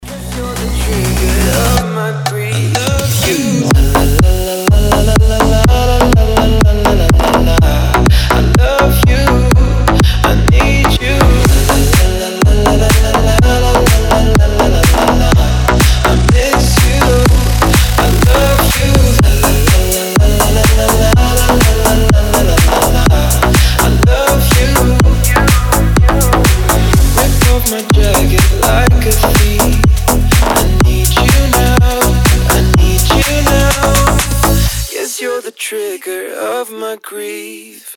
• Качество: 320, Stereo
deep house
красивый мужской голос
ремиксы
slap house